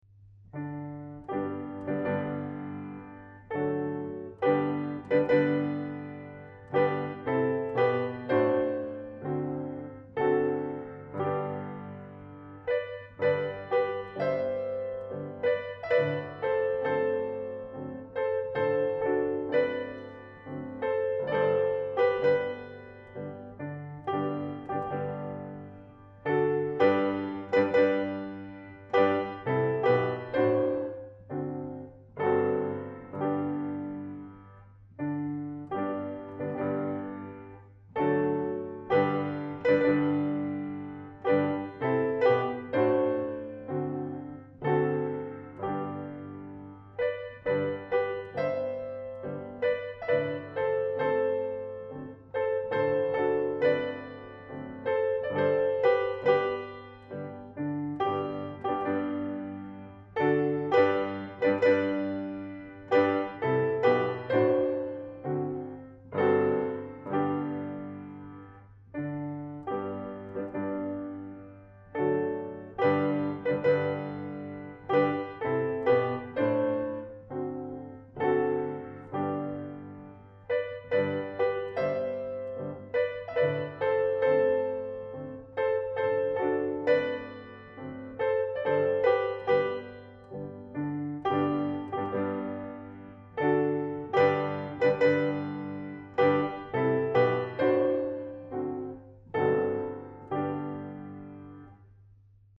Welte-Mignon, O Tannenbaum
O Christmas Tree, German carol